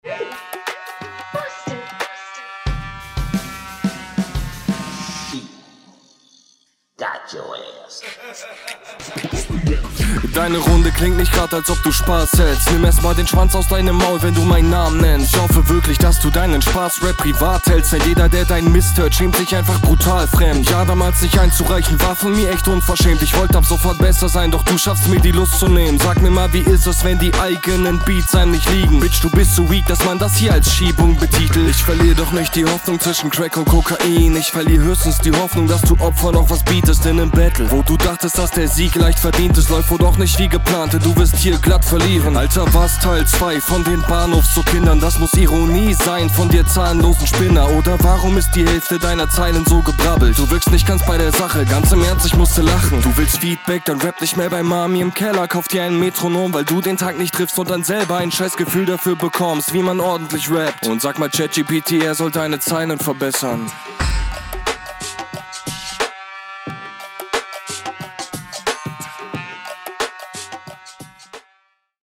Ja ok, das ist DEUTLICH überlegen, viel sichererer Flow, prägnanter Stimmeinsatz, gute Reimtechnik, cleane Audioqualität …